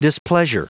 Prononciation du mot displeasure en anglais (fichier audio)
displeasure.wav